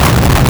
Player_Glitch [75].wav